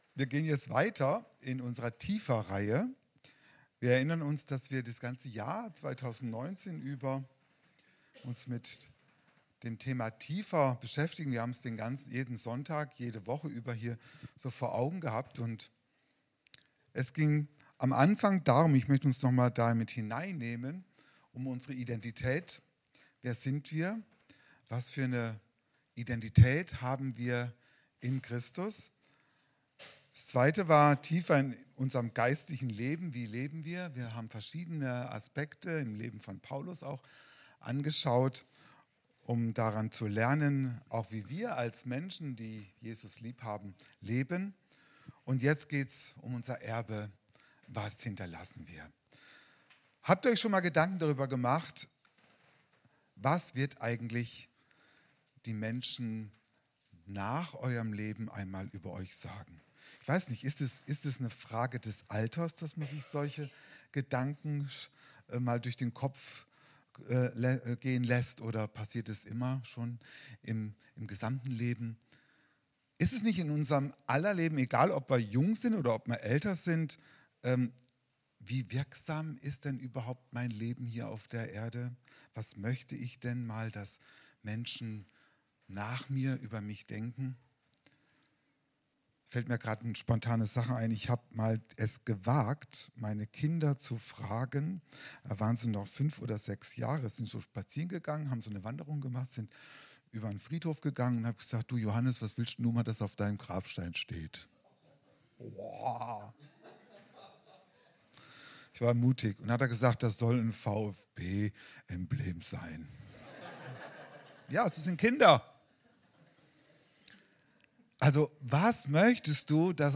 Kategorie Predigten